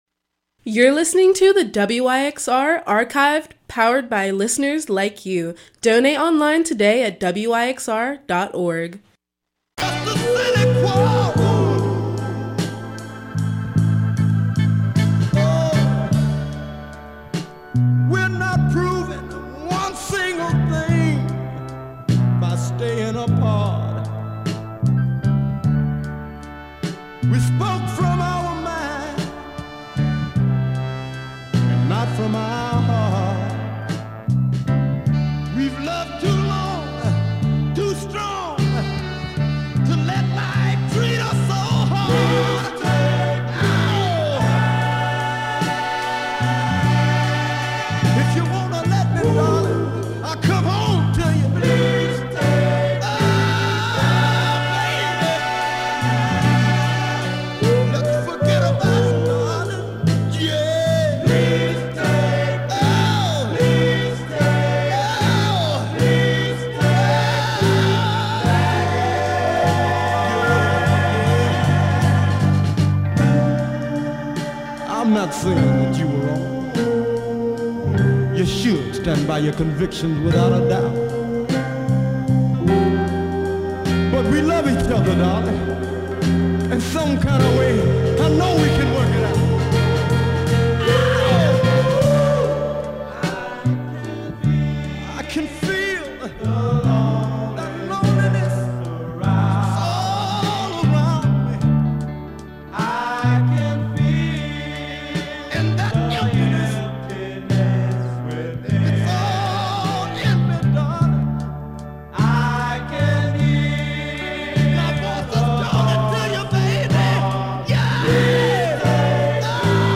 Soul Gospel Rock